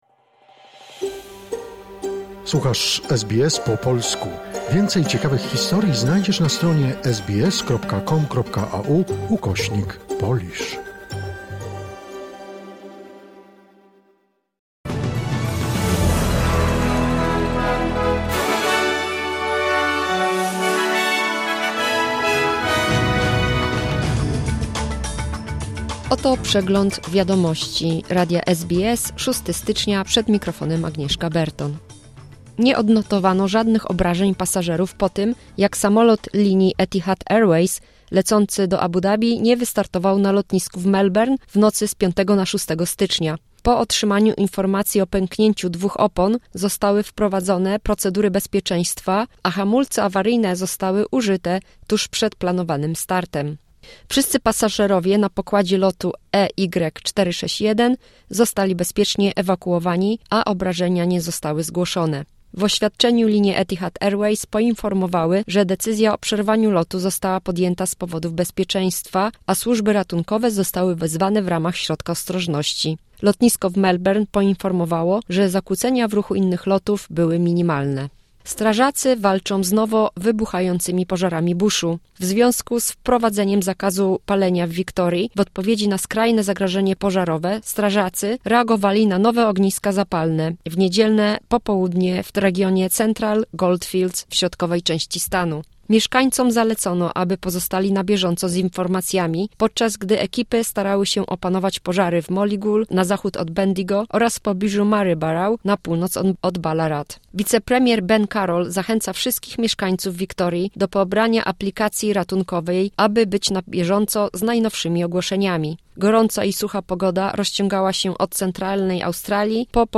Wiadomości 6 stycznia 2025 SBS News Flash